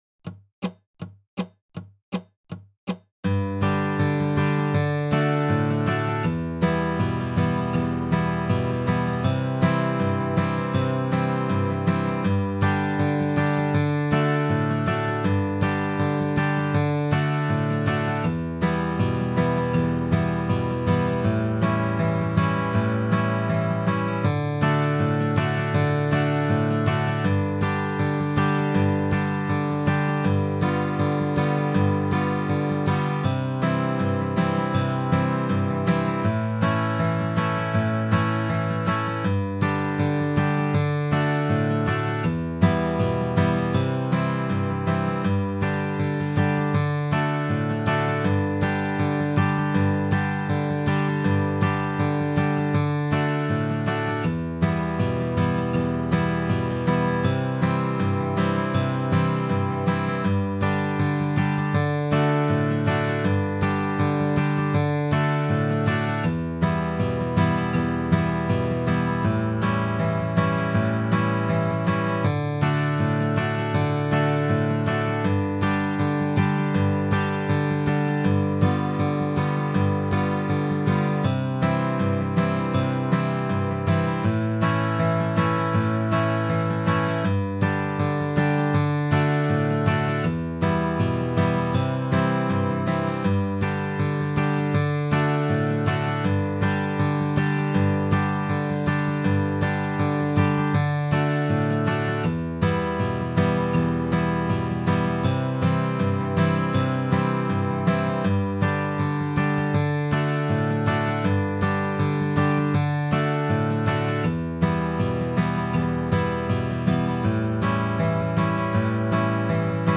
February 2025 Dobro Zoom Workshops
SM - Don't Think Twice, It's All Right - 80 BPM.mp3